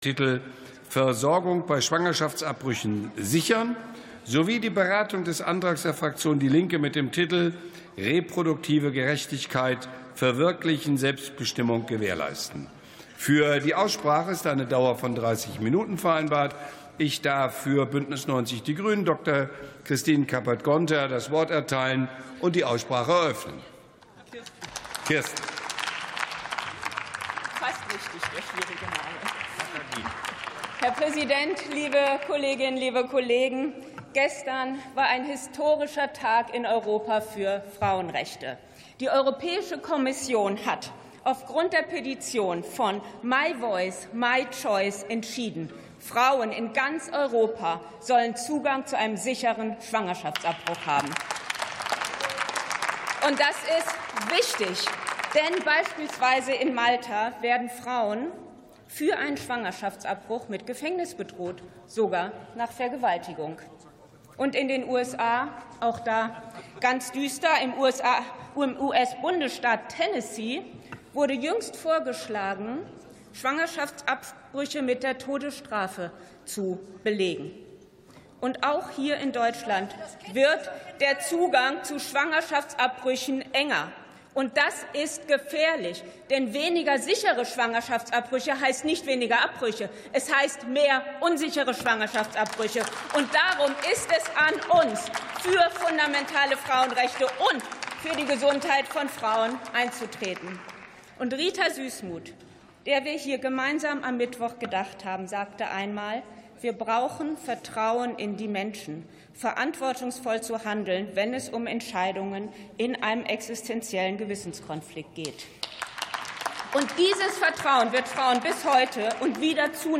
60. Sitzung vom 27.02.2026. TOP ZP 17, 18: Schwangerschaftsabbrüche, reproduktive Gerechtigkeit ~ Plenarsitzungen - Audio Podcasts Podcast